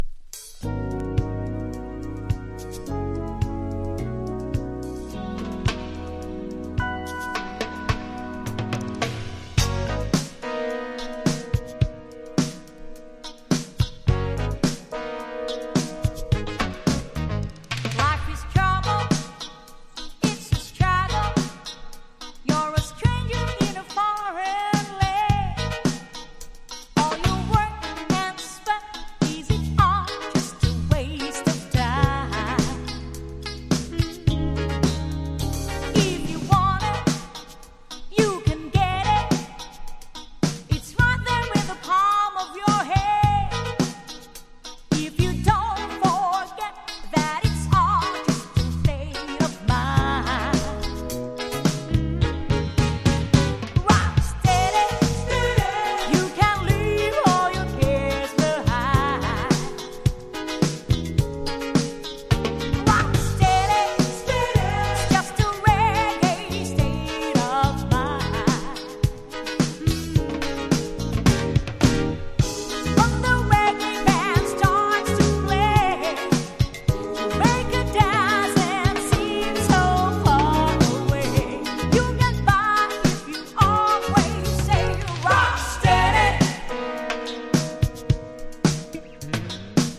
心地良いAOR～アーバン・サウンドを展開!!